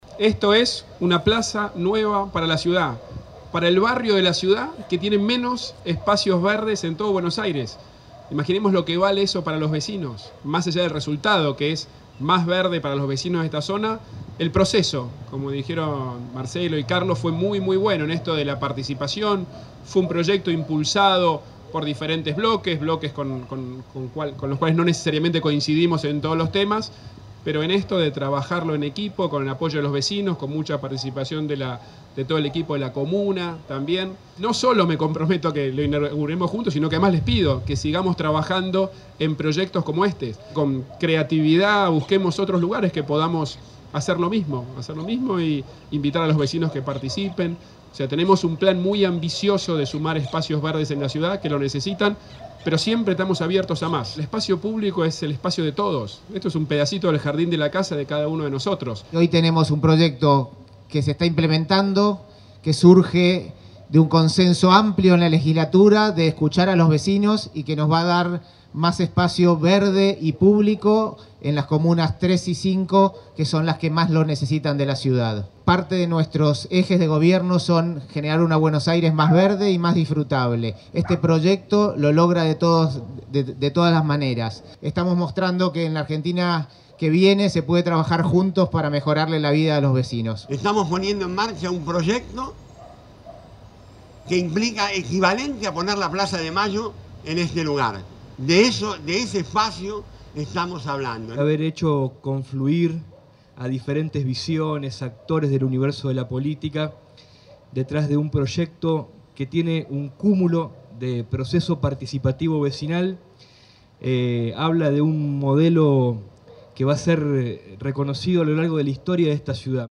Horacio Rodríguez Larreta presentó el proyecto del Parque de la Estación junto a vecinos